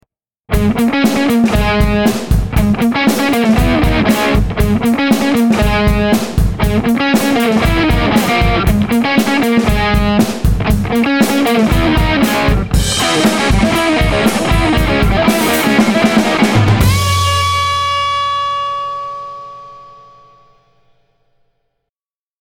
Written in D♭, this 70’s style rock riff uses palm muting, pentatonics, and a focus on upbeats.
LRT-004-Guitar-and-Drums.mp3